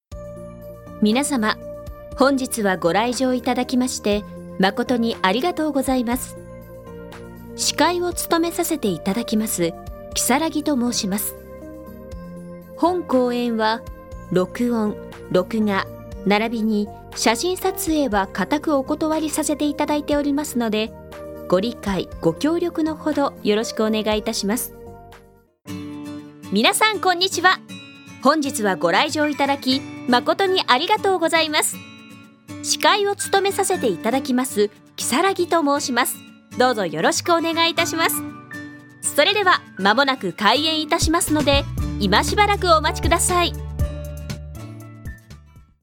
通常版 演技版 BGMあり